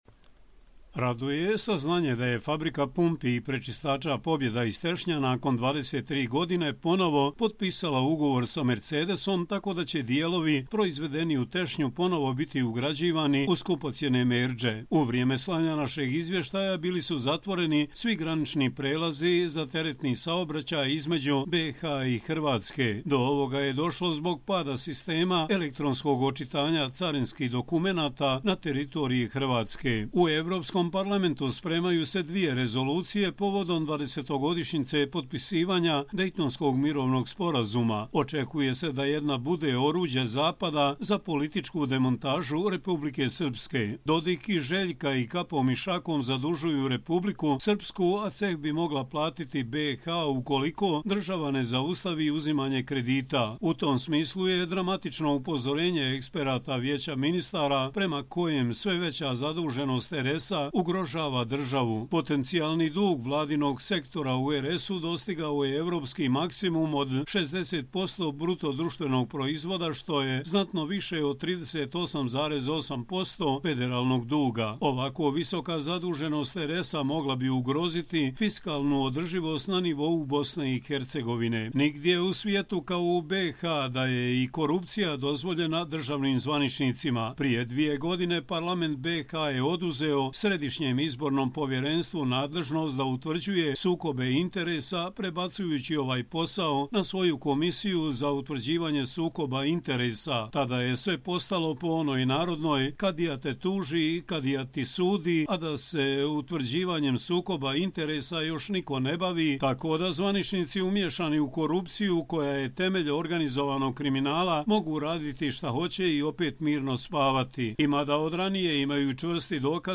Audio izvještaji